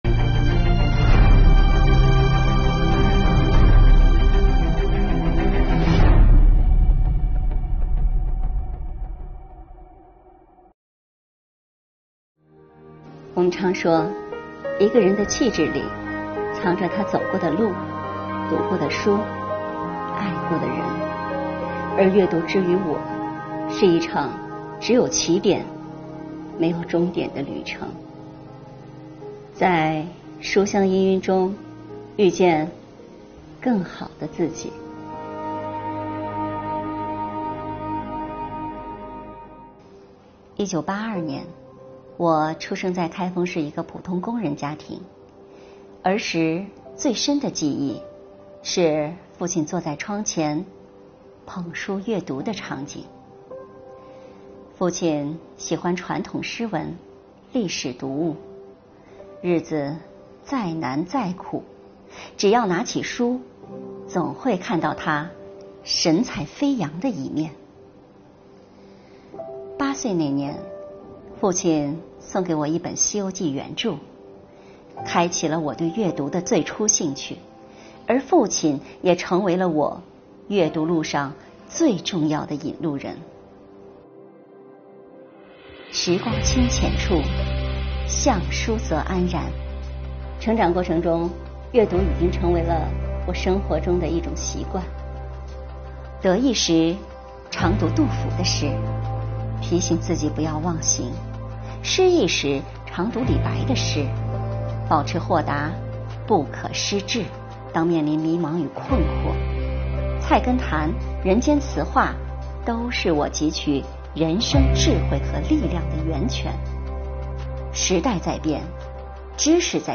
诵读征集活动优秀作品
该作品以第一人称讲述的形式，通过温馨、诗意、大气、阳光等镜头特点，娓娓道来个人的阅读故事，突出阅读这一优良家风的传承性，展示全民阅读、书香中国在普通个体身上折射出的民族气质、文化自信等。